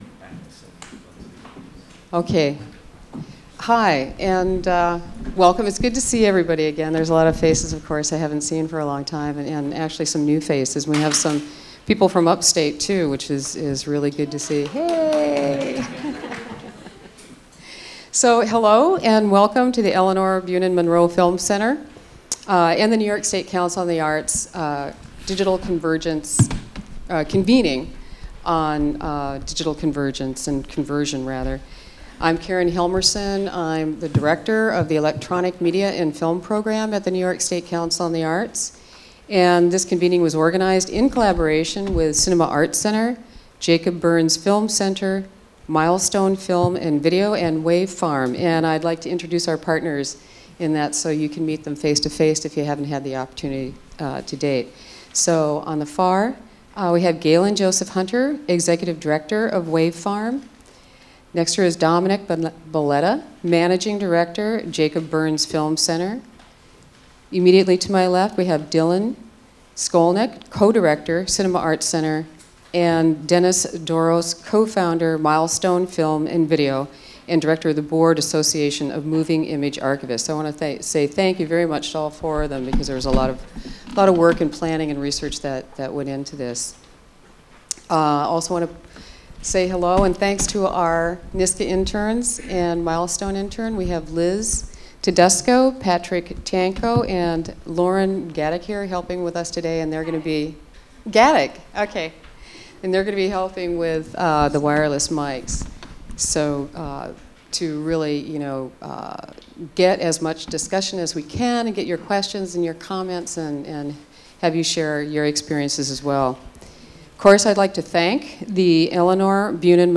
Elinor Bunin Monroe Film Center